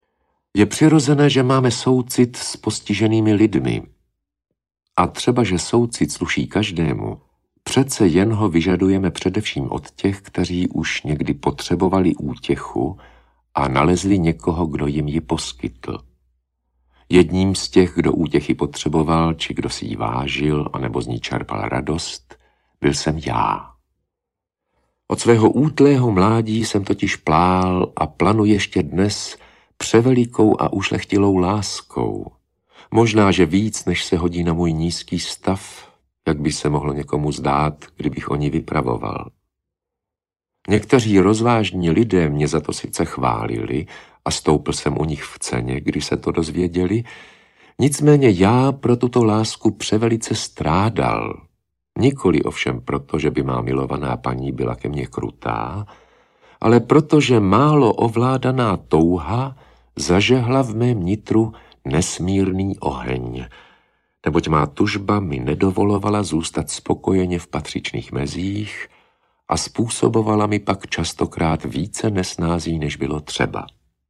Audiobook
Read: Rudolf Pellar